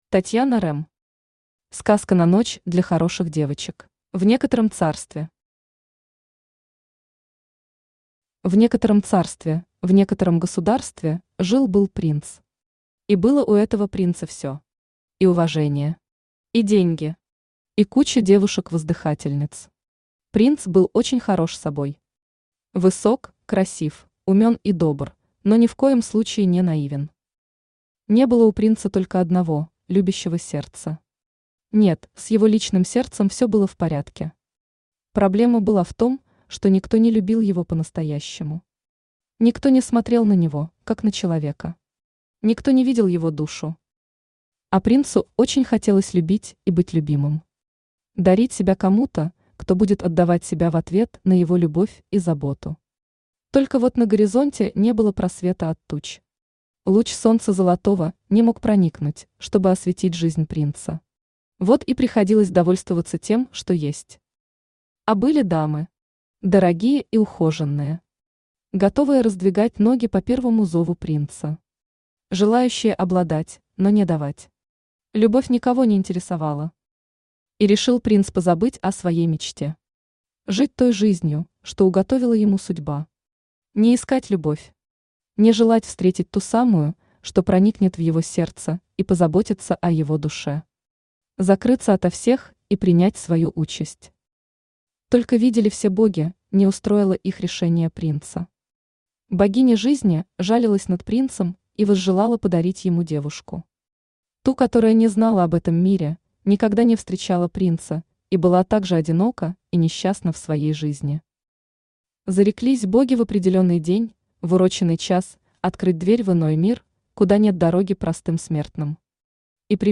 Aудиокнига Сказка на ночь для хороших девочек Автор Татьяна Рэм Читает аудиокнигу Авточтец ЛитРес.